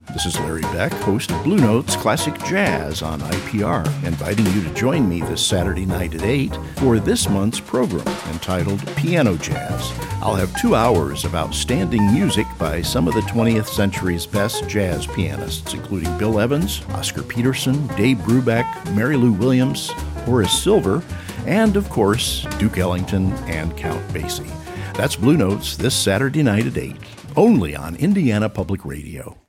Bluenotes-for-May-2025-Web-promo.mp3